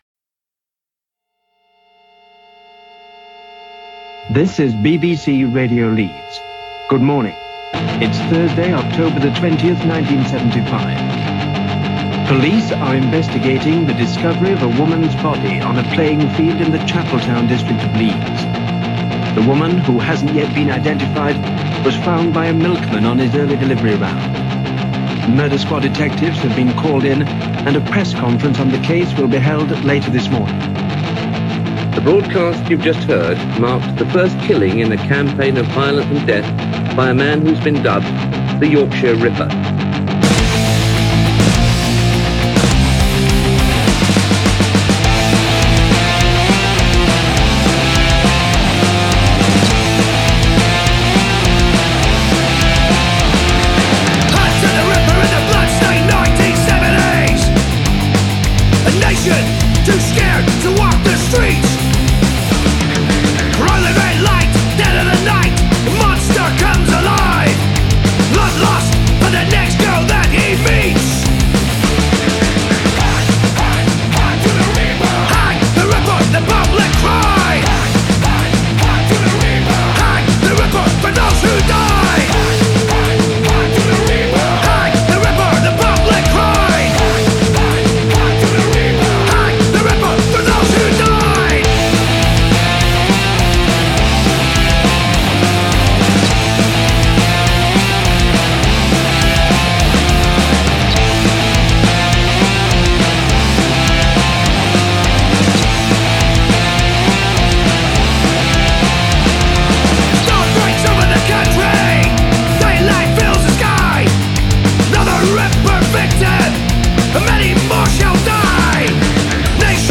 Oi! Band
trockenen britischer Oldschool-Skinhead-Oi! im Midtempo.
Sound ist ausbaufähig, aber hey!…. is halt oldschool !